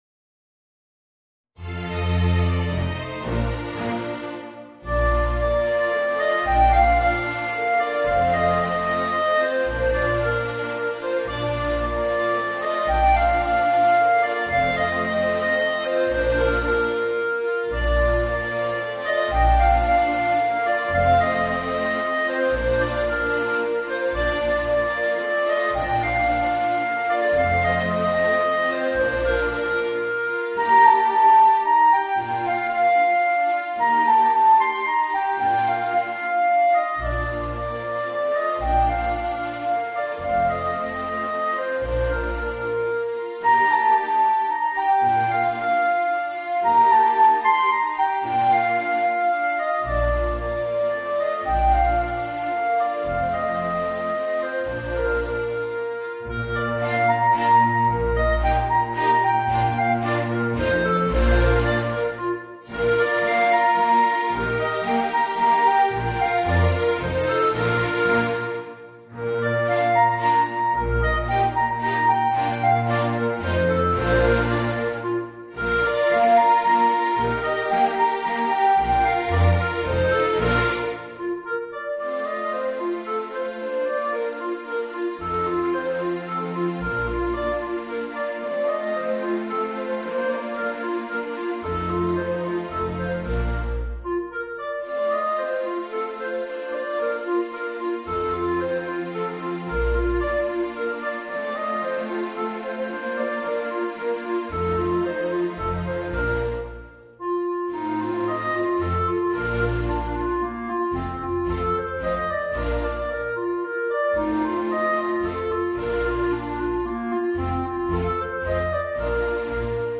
Voicing: Clarinet and String Orchestra